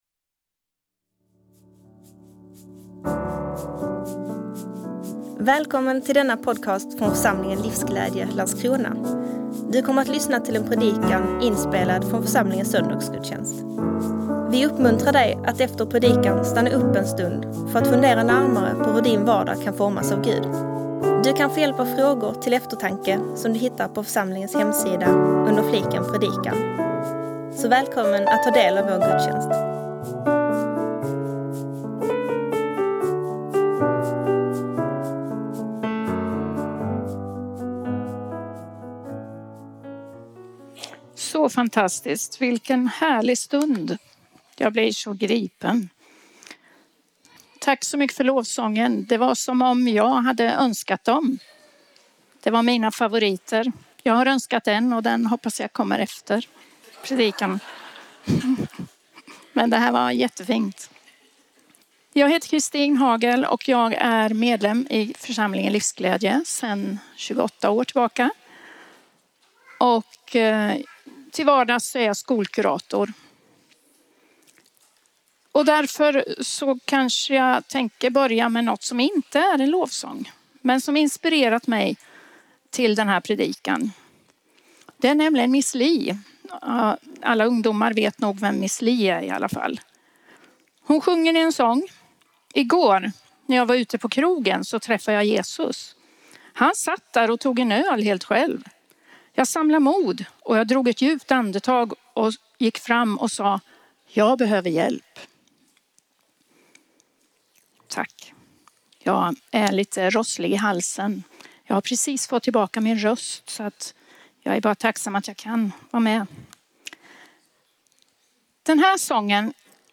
predikar från Jona bok.